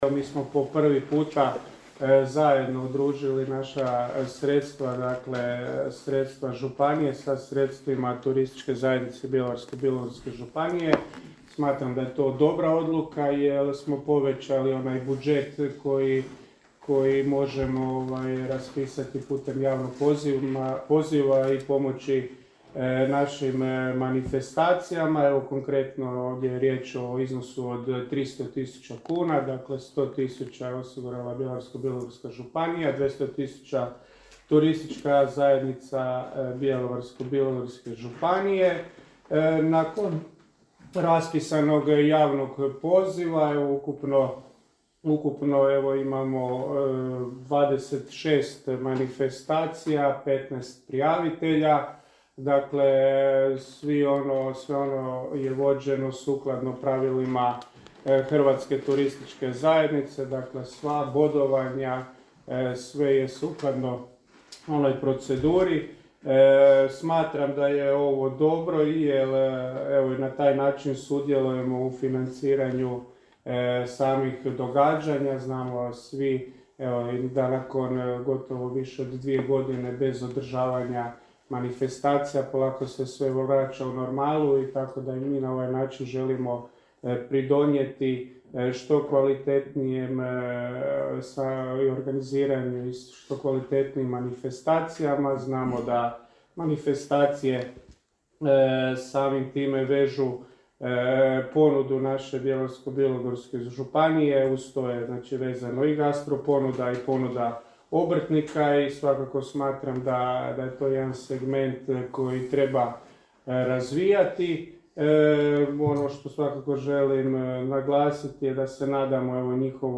U Bjelovaru je početkom svibnja održano potpisivanje ugovora s prijaviteljima za dodjelu potpora regionalnim i lokalnim događajima u 2022. godini. Uvodno se svima obratio župan Marko Marušić inače i predsjednik TZ BBŽ: